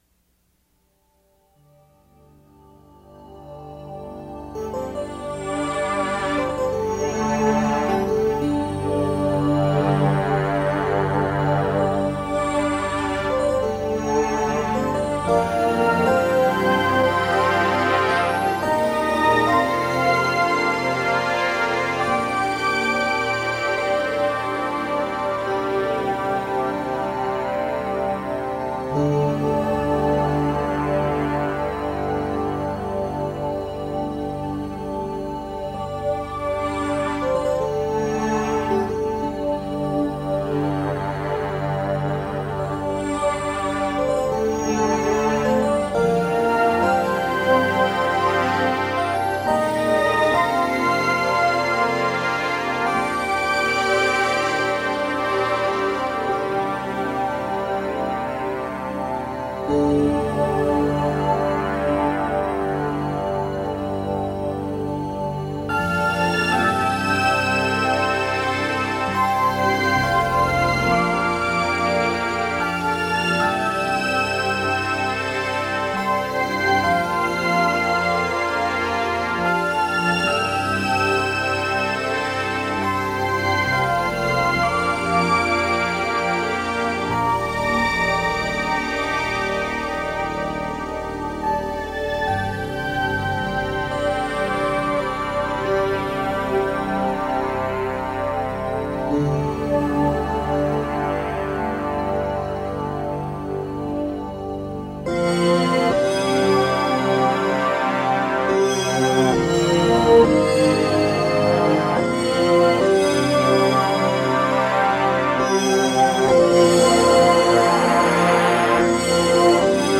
A modestly ambitious (at least, for this amateur musician and would-be recording studio engineer) 4-track home recording of some pieces quite on my mind at the time. The master tape had deteriorated somewhat over the years prior to its eventual transfer to digital media in the mid-2000s.